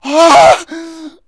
PAINLEG11.WAV